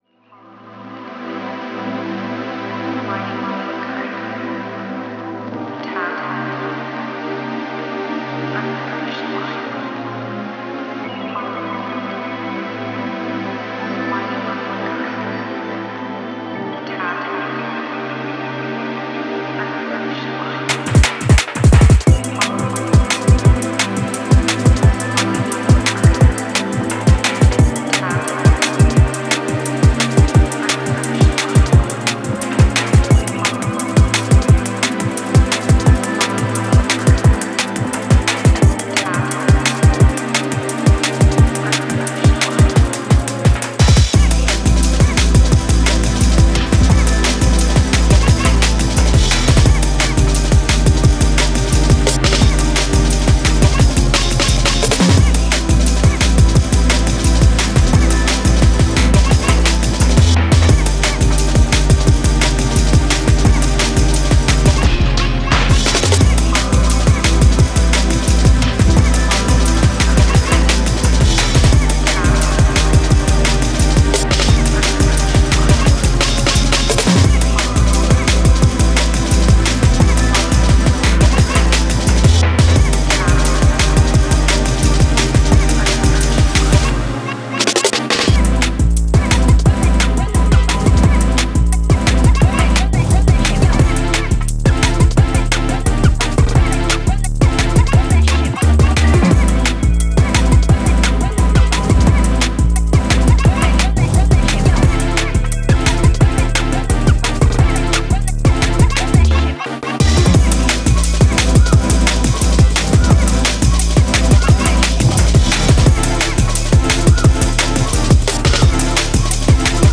a jungle(ish) track with an early 2000's vibe